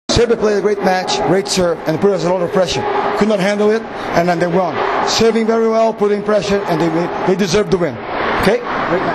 IZJAVA BERNARDA REZENDEA